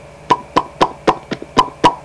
Pop Sound Effect
Download a high-quality pop sound effect.
pop-1.mp3